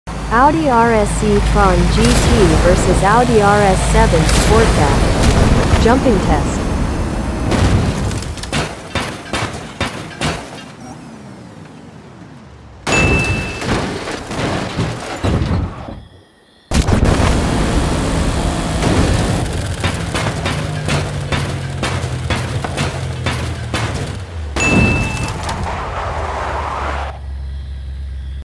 The 2021 Audi RS e tron sound effects free download
The 2021 Audi RS e-tron GT vs. The 2021 Audi RS 7 Sportback Part 6 in Forza Horizon 5 Using Xbox Wireless Controller Gameplay.